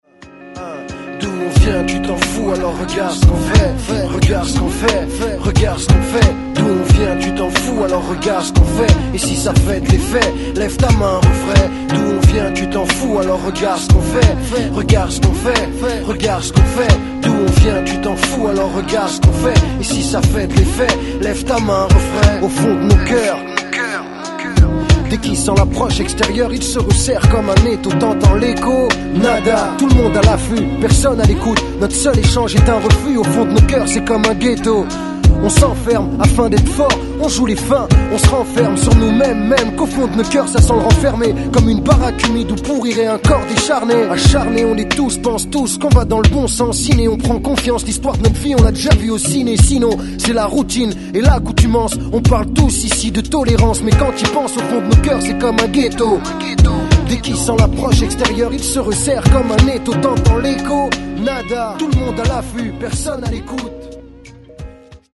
Catégorie : Rap